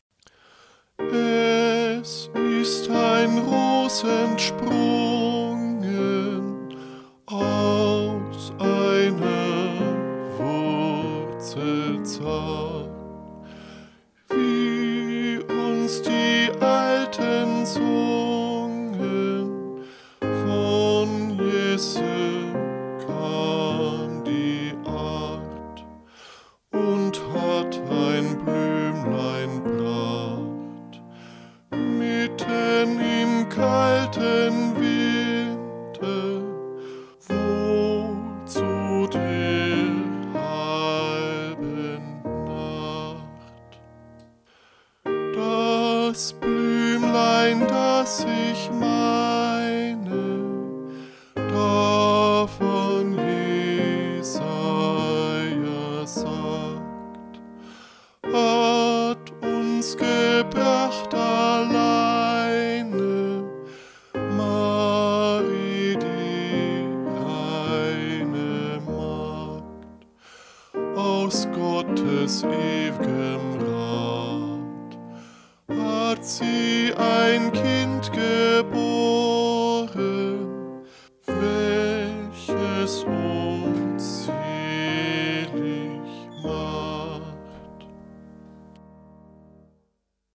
Eingesungen: Liedvortrag (